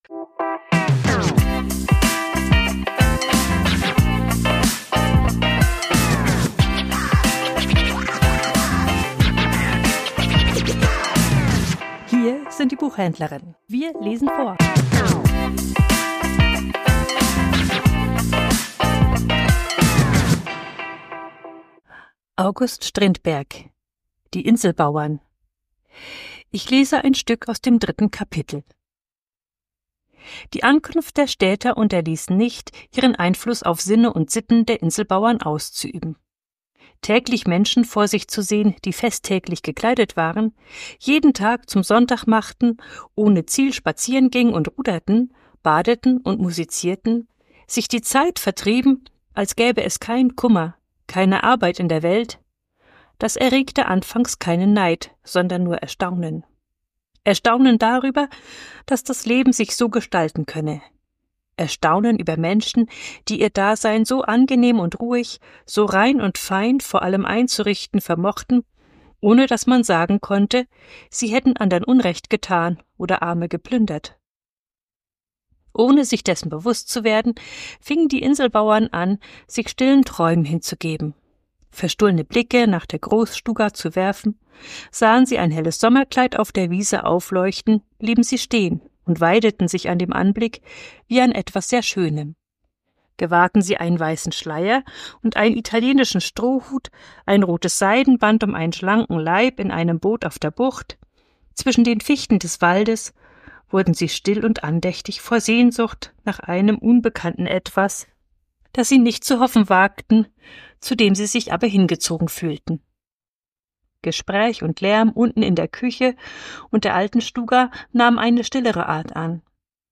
Vorgelesen: Die Inselbauern ~ Die Buchhändlerinnen Podcast